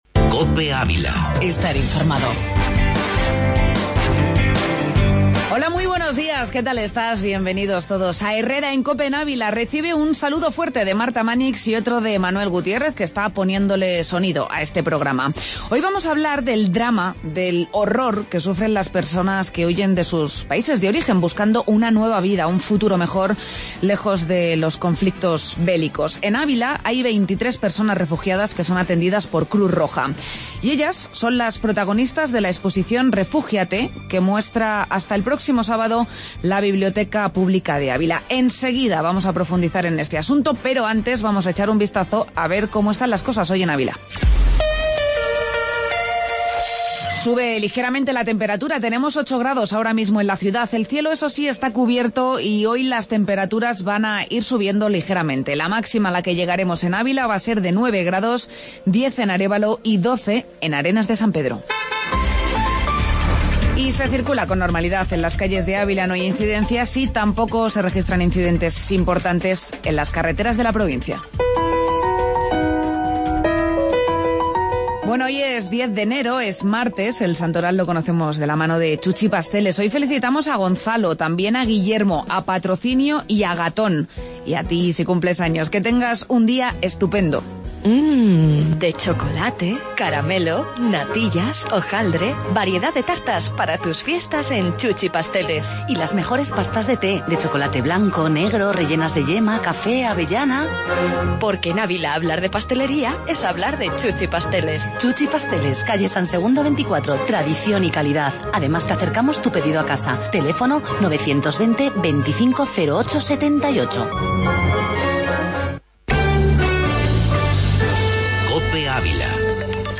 AUDIO: Entrevista Refugiados Cruz Roja